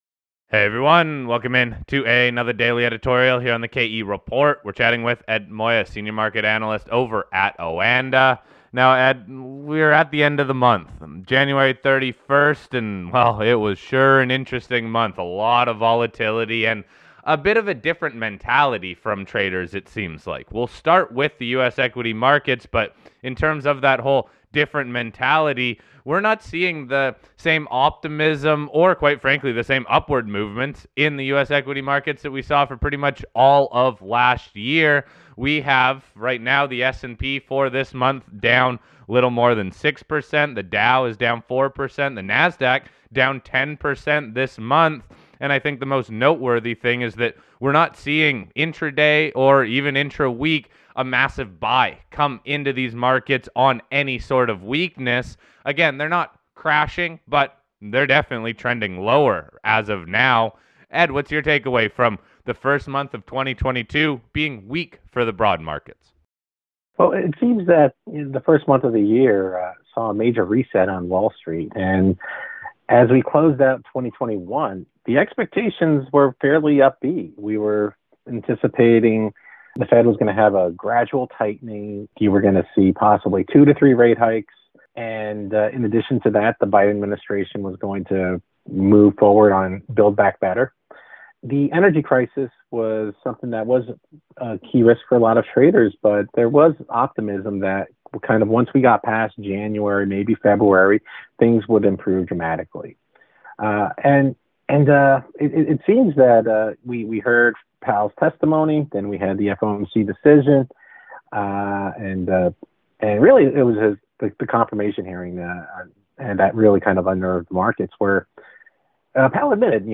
To wrap up the interview